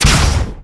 fire_gun2_rank1.wav